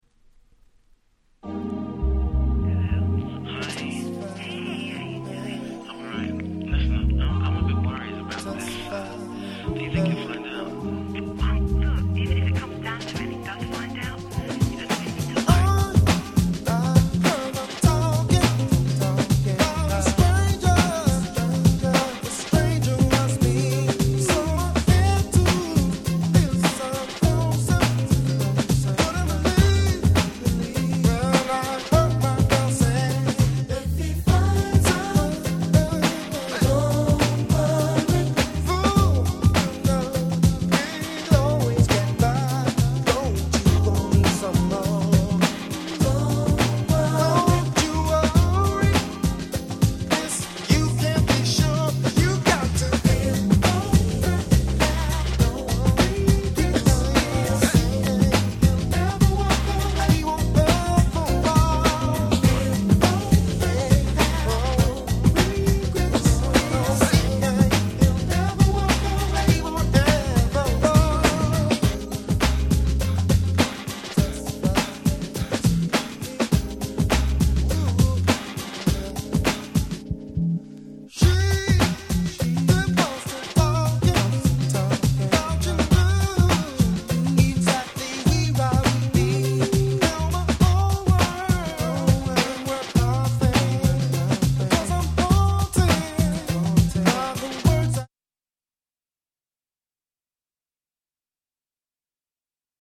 UK Soul Classics !!